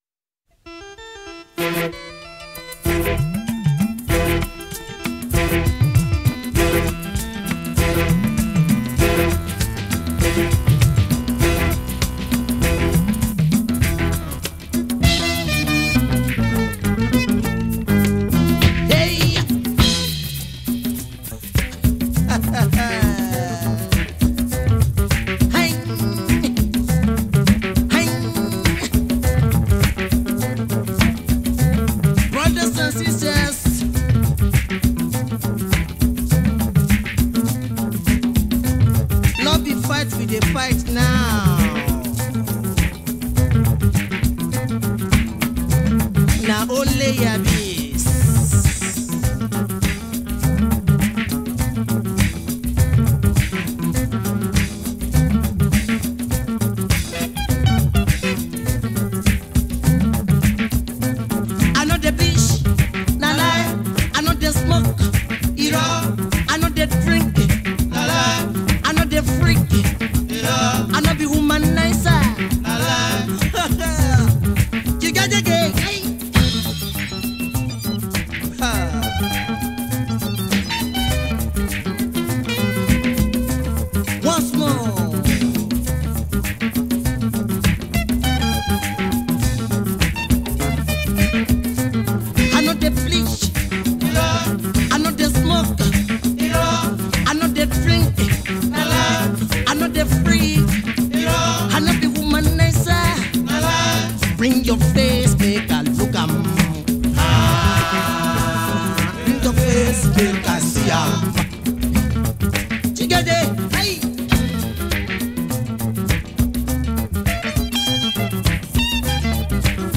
is a Nigerian Jùjú musician.